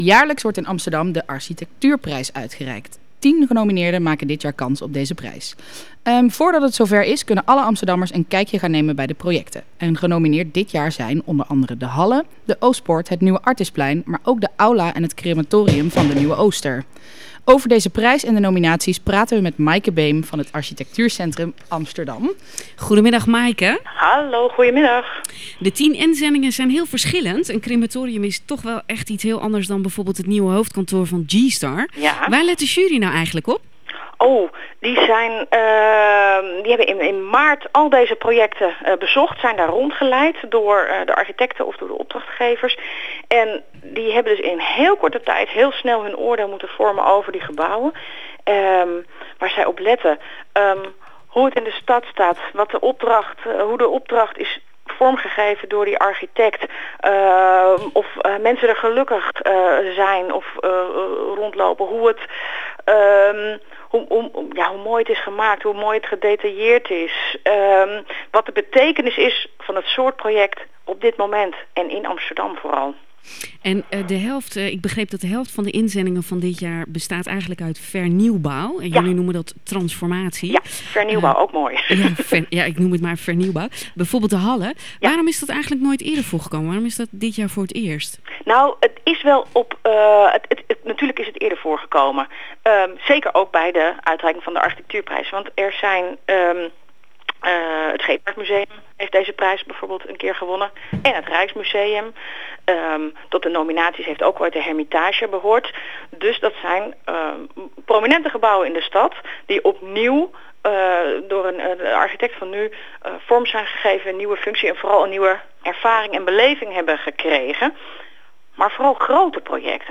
In het Ritme van de Stad spreken we met haar.